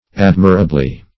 admirably - definition of admirably - synonyms, pronunciation, spelling from Free Dictionary
Admirably \Ad"mi*ra*bly\, adv.